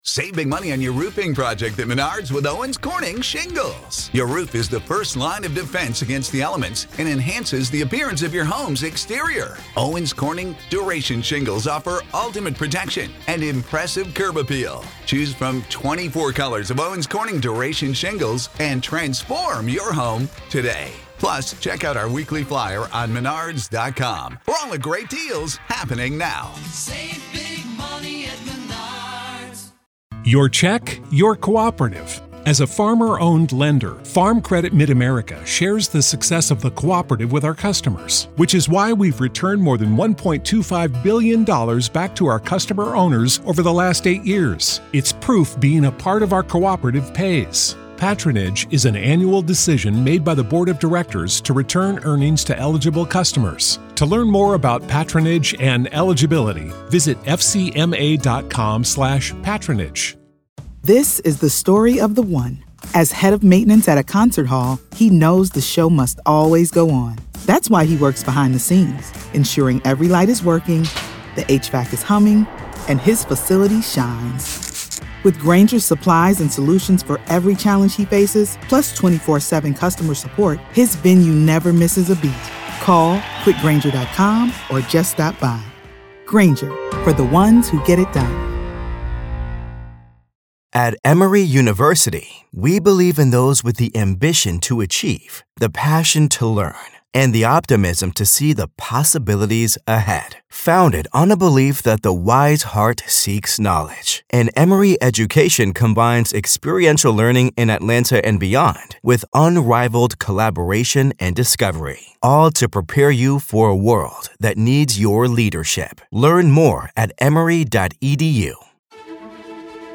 Motions Hearing Part 1